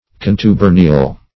contubernial - definition of contubernial - synonyms, pronunciation, spelling from Free Dictionary
Contubernial \Con`tu*ber"ni*al\ (k[o^]n`t[-u]*b[~e]r"n[i^]*al),